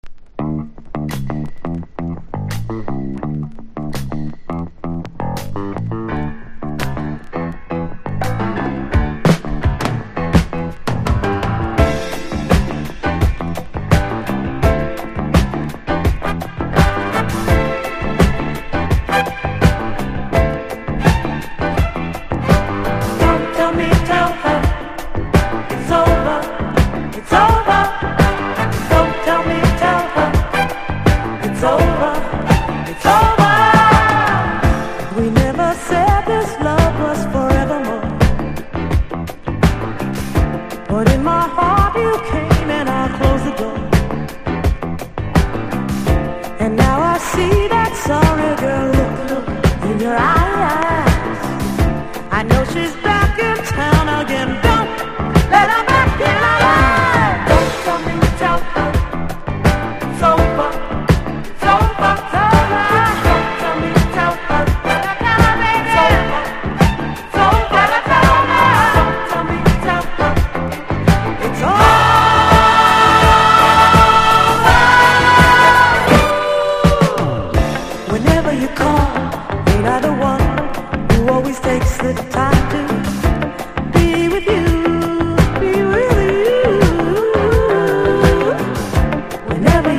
フリーソウルファンにもおすすめ、華やかでソウルフルな名曲です。